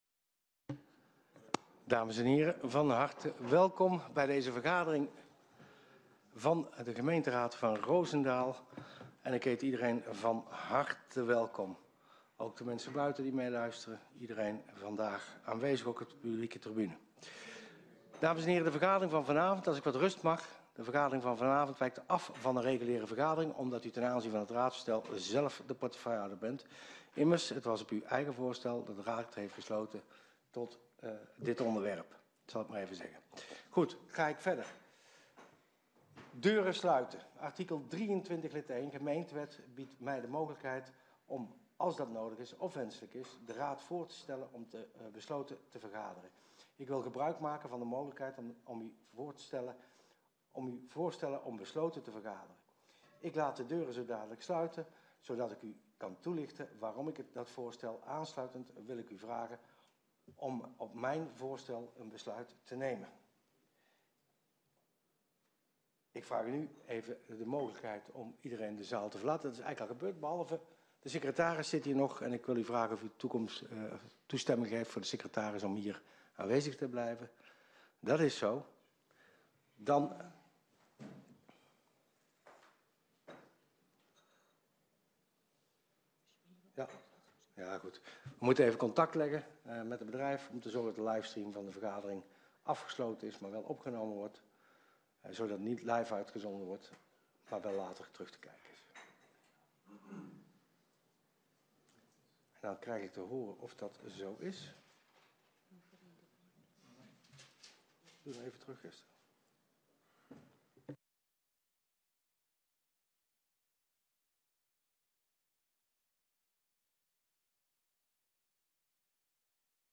Gemeenteraad 07 april 2025 19:00:00, Gemeente Roosendaal
Locatie: Raadzaal Voorzitter: M. Buijs